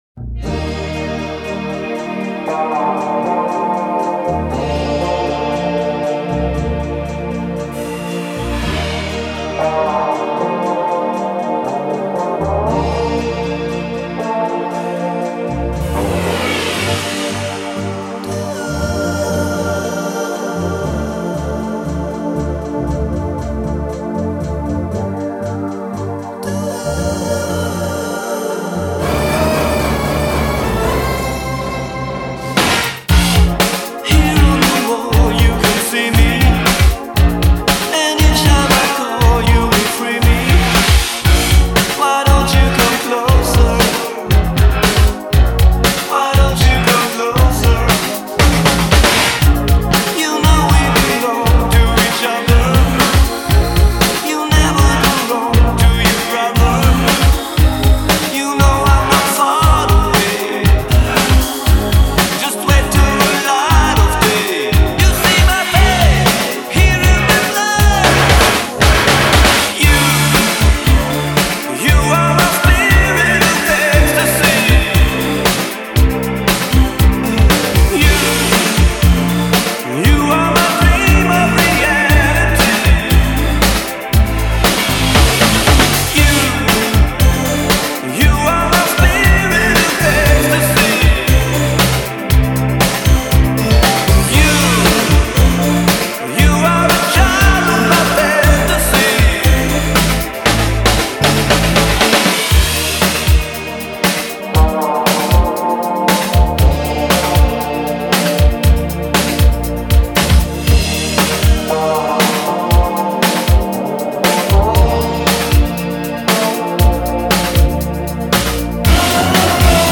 Жанр: Pop/Synth-pop